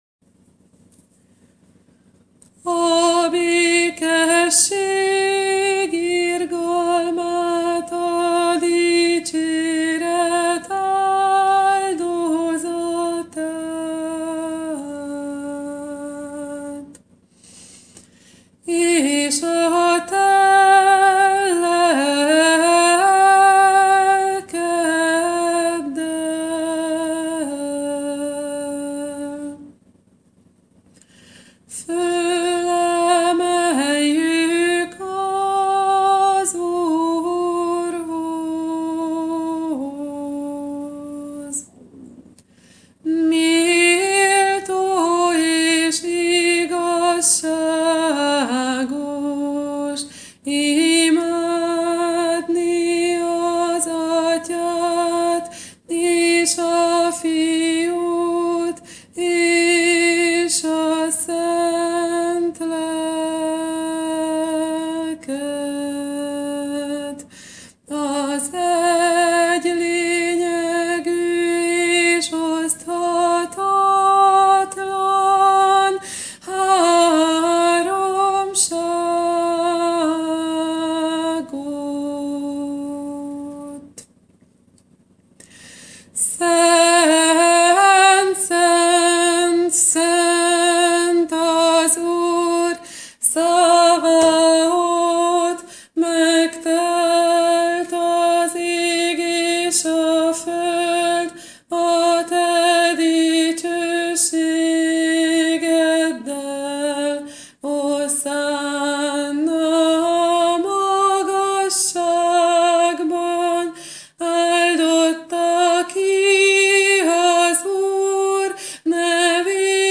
“V” Liturgia – egyszólamú valaami dallamokkal (mp3)
14-anafora-valaam.mp3